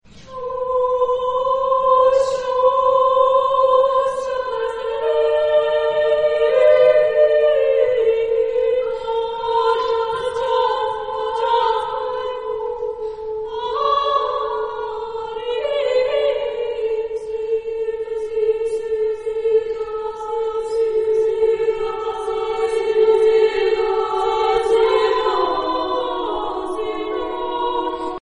Género/Estilo/Forma: Madrigal ; Profano
Tipo de formación coral: SSAA  (4 voces Coro femenino )
Tonalidad : si menor
Consultable bajo : 20ème Profane Acappella